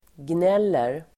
Uttal: [gn'el:er]